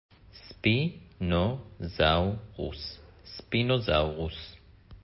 ספי-נו-זאו-רוס